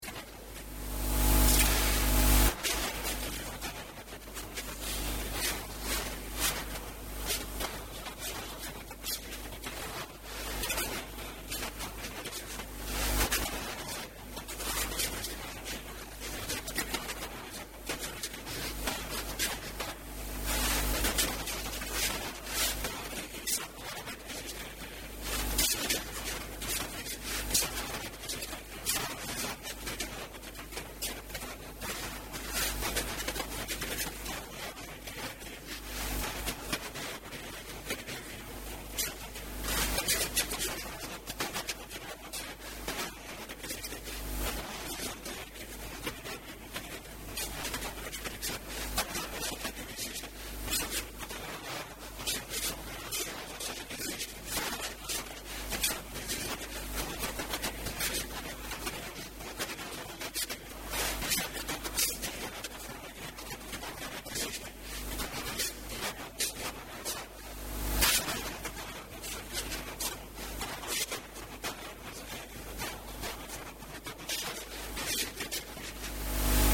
O Secretário Regional da Saúde afirmou hoje, na Assembleia Legislativa, que existem nas unidades de saúde dos Açores “inúmeros exames em arquivo”, que nunca ninguém abriu, nem viu.
Luís Cabral, que prestava esclarecimentos aos deputados no âmbito de uma interpelação do Bloco de Esquerda ao Governo sobre a defesa e sustentabilidade do Serviço Regional de Saúde, apontou esta situação como um exemplo daquilo que é necessário fazer no sentido da racionalização de despesas no setor.